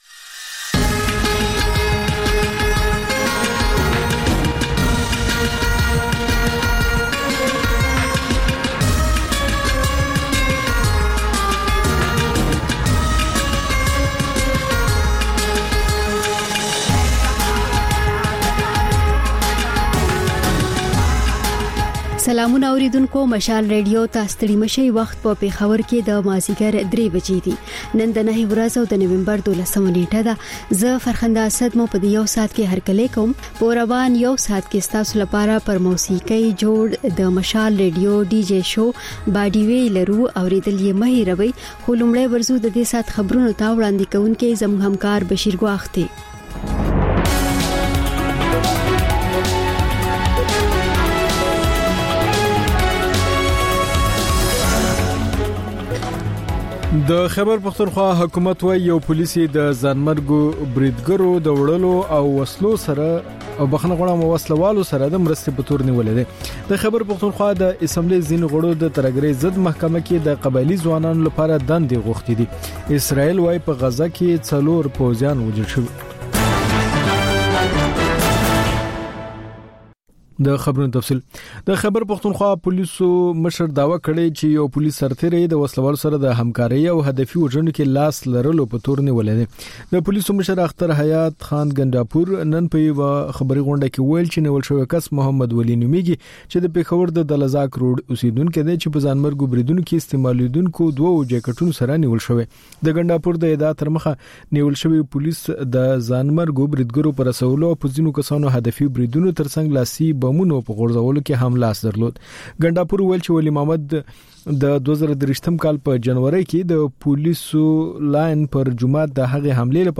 د مشال راډیو درېیمه یو ساعته ماسپښینۍ خپرونه. تر خبرونو وروسته، رپورټونه او شننې خپرېږي.